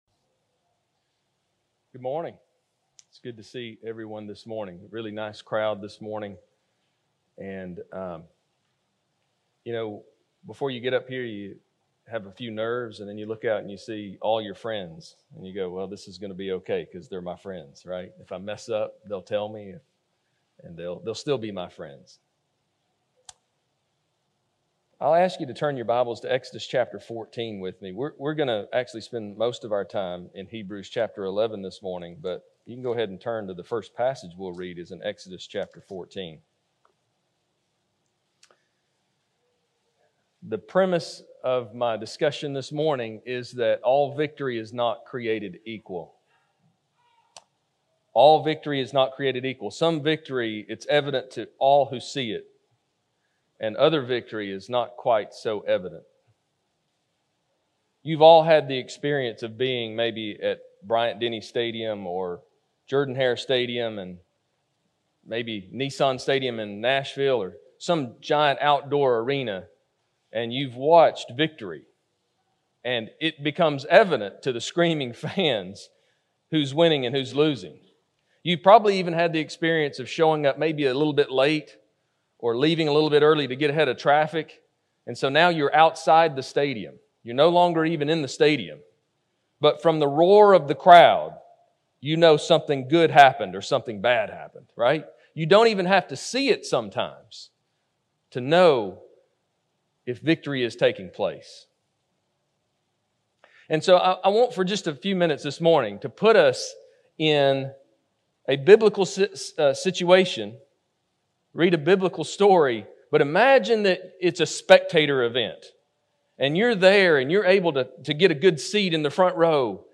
Recording of a sermon